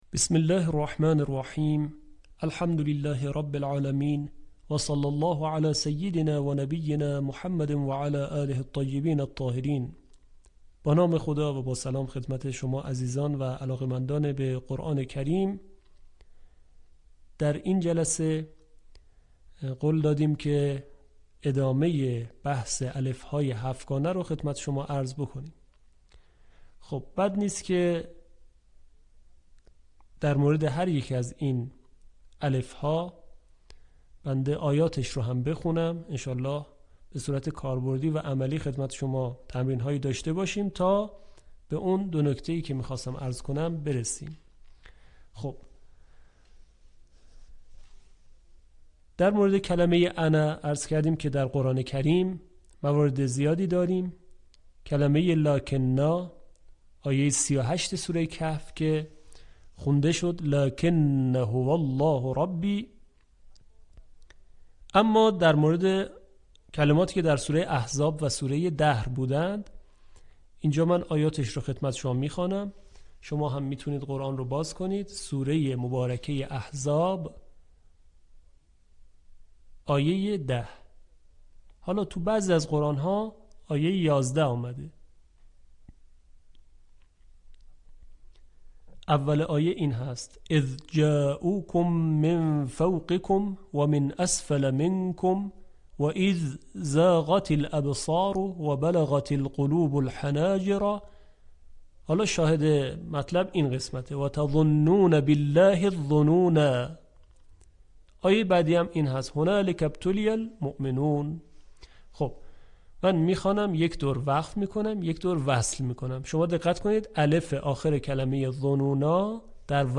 به همین منظور مجموعه آموزشی شنیداری (صوتی) قرآنی را گردآوری و برای علاقه‌مندان بازنشر می‌کند.
آموزش تجوید